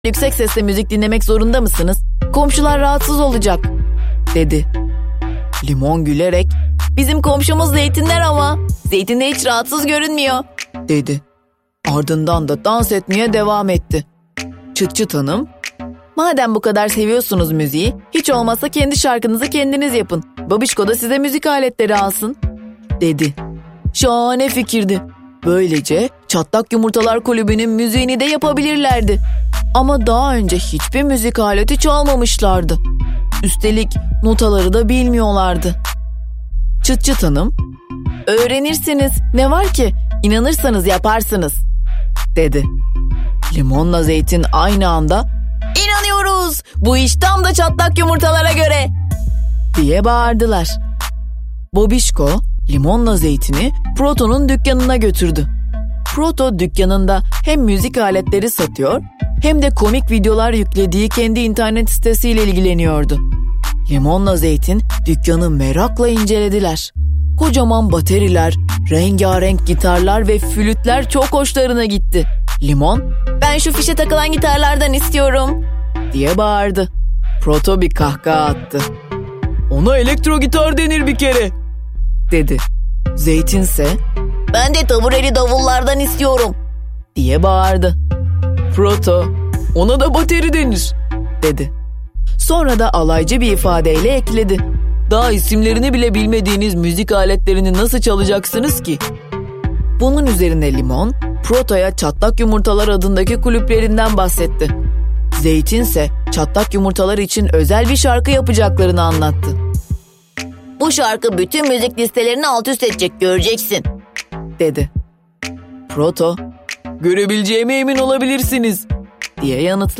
Limon ile Zeytin | Cımtıstak – Abla Sesinden Dinle